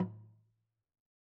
LogDrumLo_MedM_v2_rr2_Sum.wav